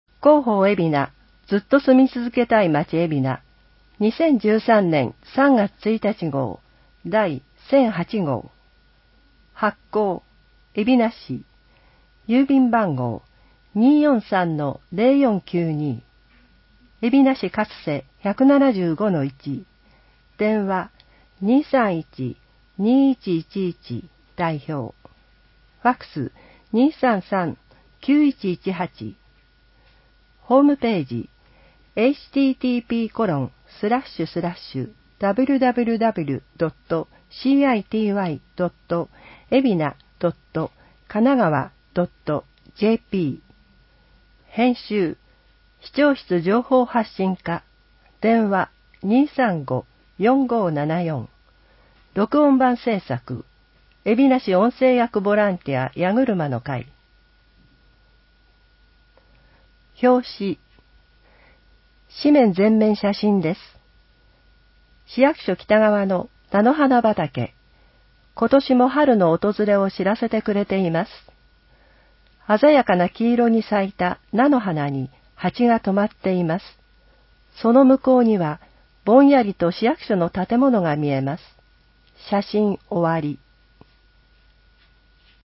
※音声版は、音声訳ボランティア「矢ぐるまの会」の協力により、同会が視覚障がい者の方のために作成したものを登載しています。